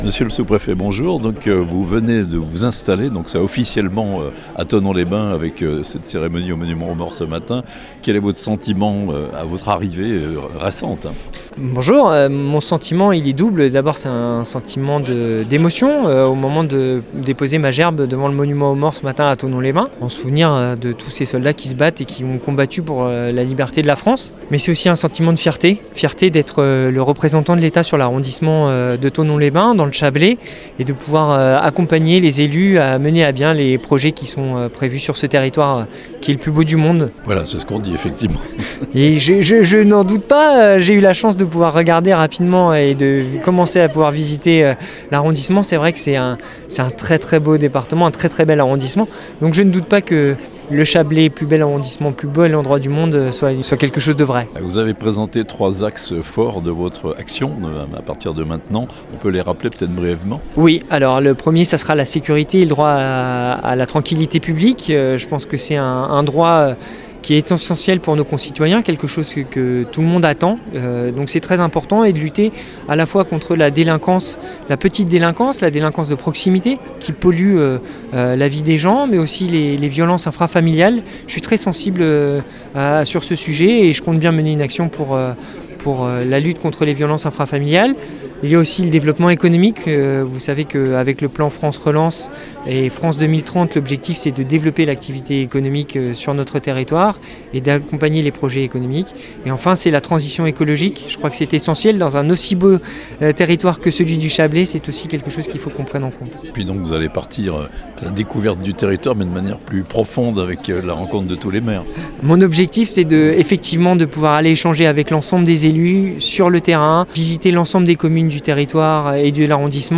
itw-nouveau-s-prefet-de-thonon-62118.mp3